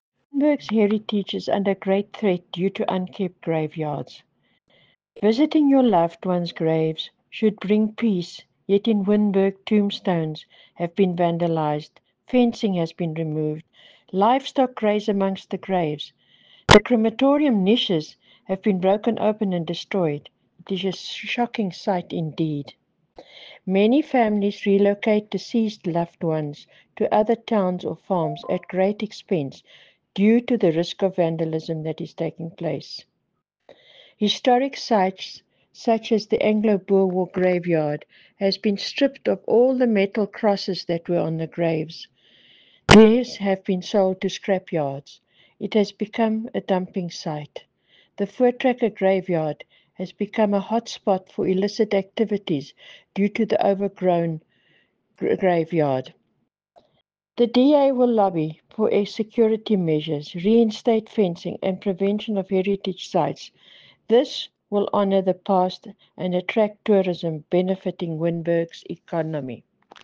Afrikaans soundbites by Cllr Brunhilde Rossouw and